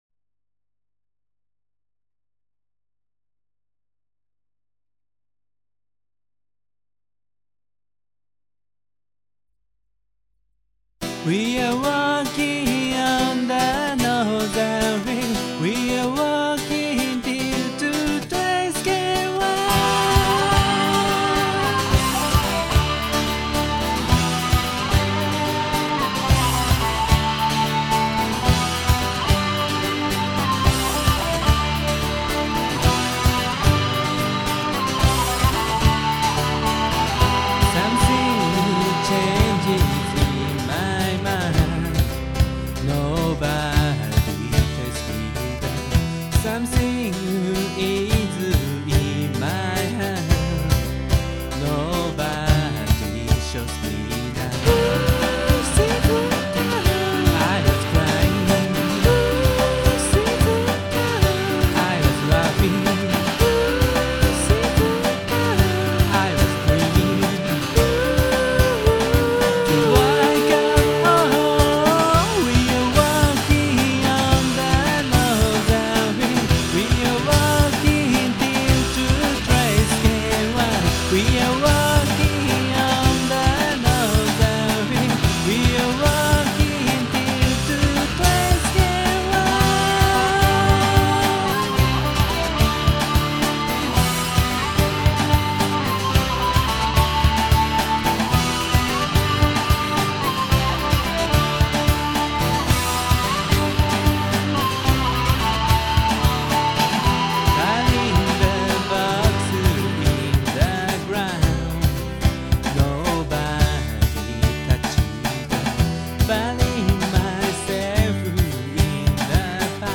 どこかの国の民謡のようなミディアムテンポのバラード。